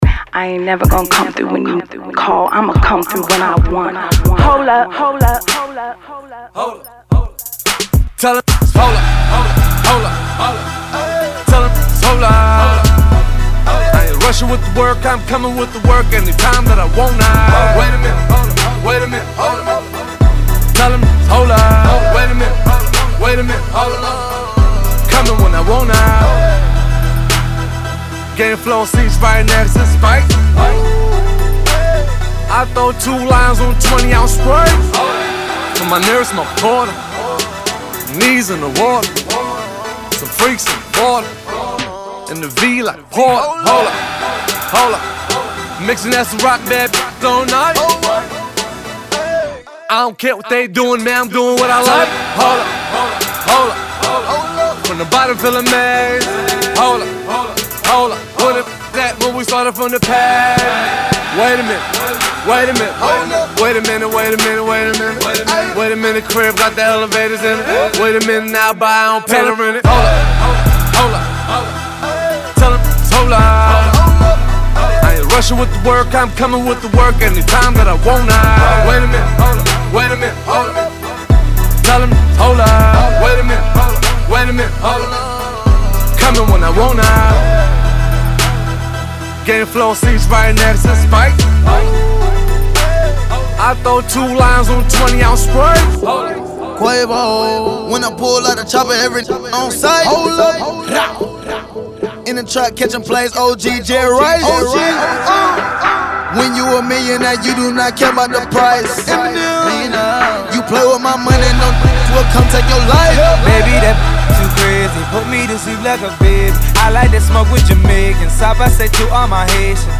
SINGLESHIP-HOP/RAP